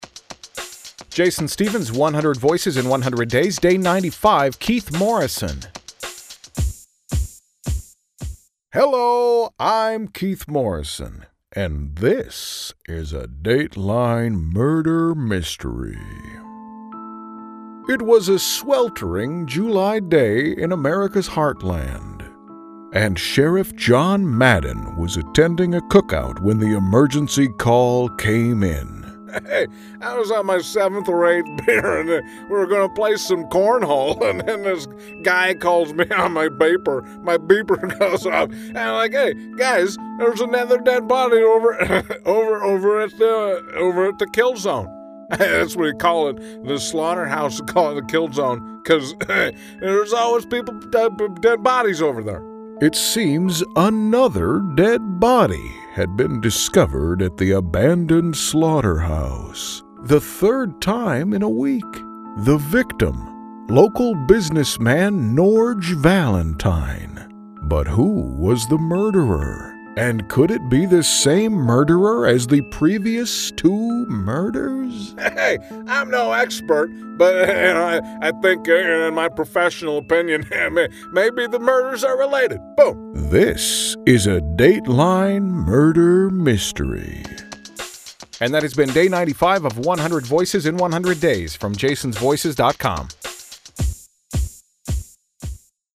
Day 95 – Keith Morrison Impression
Some consider his delivery to be a touch dramatic, but he seems to be a great sport.
You’ll also hear special guest John Madden, portraying a small town sheriff in today’s episode.
Tags: celebrity impersonations, Keith Morrison impression, voice matching